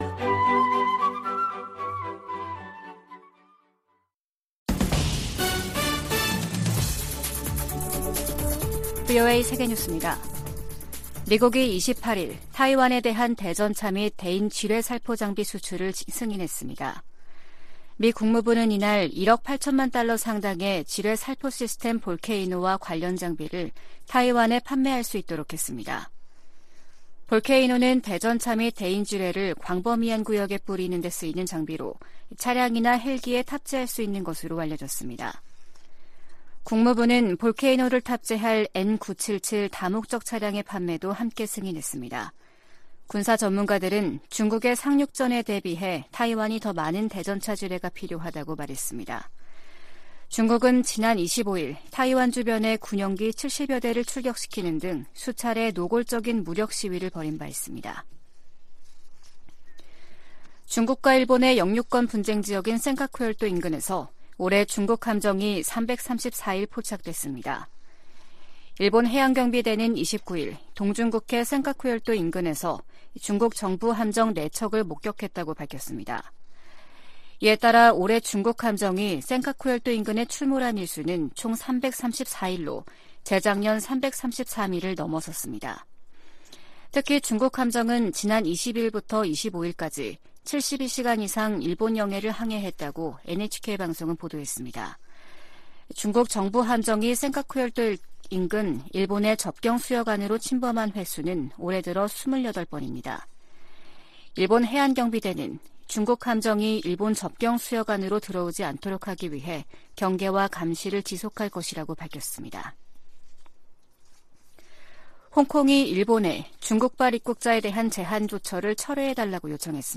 VOA 한국어 아침 뉴스 프로그램 '워싱턴 뉴스 광장', 2022년 12월 30일 방송입니다.